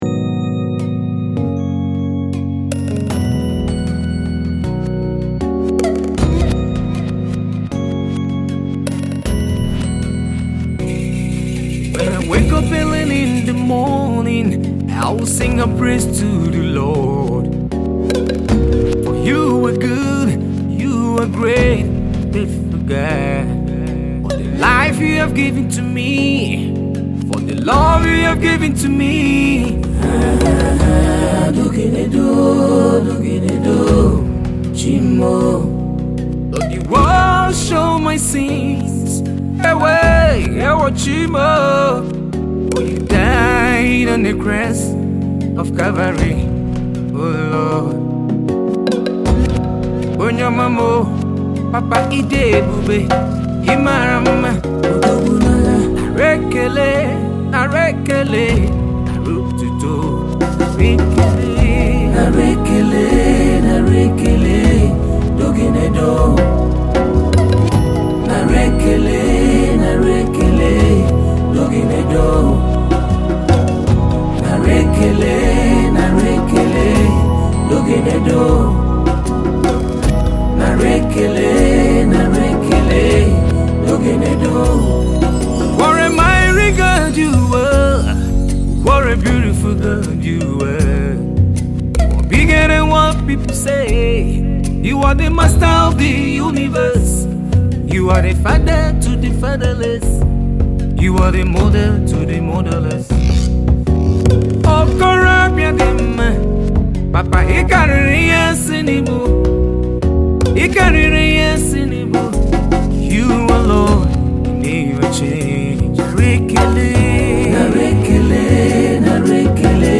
Gospel
He is a passionate Worshiper with a rich voice.